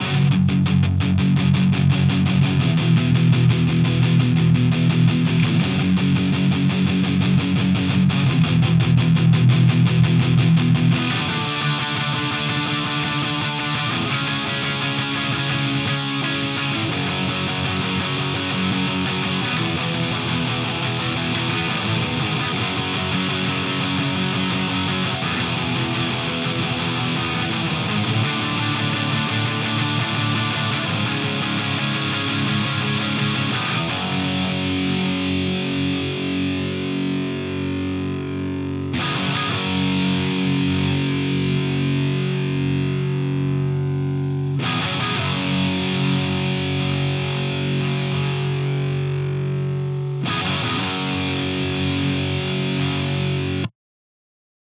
Bluecat is the left channel. LePou is the right
Modern drive
Cab sims are all NaDir.
The big thing I'm noticing is that the Bluecat sims are more "squashed" overall. There is less dynamic response to the incoming guitar tone, and the noise floor is a lot higher.